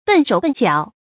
笨手笨腳 注音： ㄅㄣˋ ㄕㄡˇ ㄅㄣˋ ㄐㄧㄠˇ 讀音讀法： 意思解釋： 形容動作不靈活 出處典故： 清·韓邦慶《海上花列傳》第24回：「才是 笨手笨腳 ，無啥人來搭耐裝煙。」